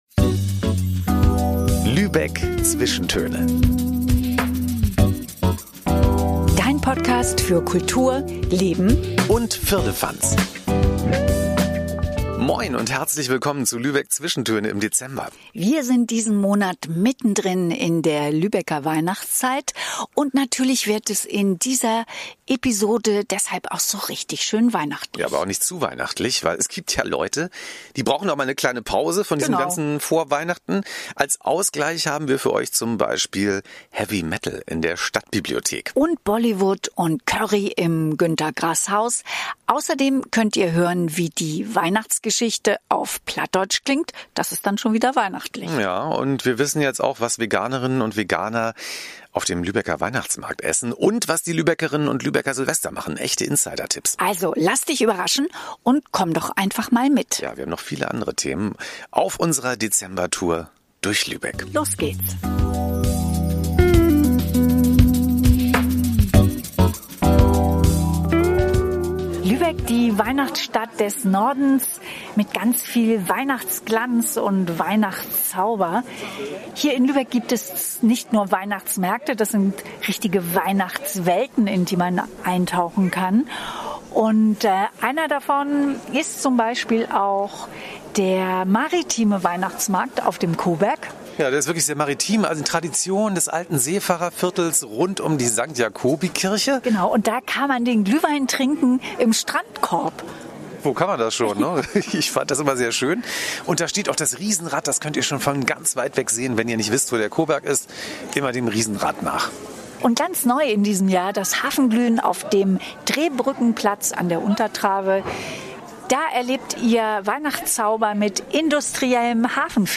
Und wenn du denkst, du kennst die Lübecker Weihnachtswelten, dann musst du uns unbedingt auf unserem akustischen Bummel über die Märkte begleiten.
Wir nehmen dich mit auf die Proben zu einer 101 Jahre alten lübschen Tradition, dem Niederdeutschen Krippenspiel. Als wilden Kontrast hörst du aber auch Heavy Metal in der Stadtbibliothek.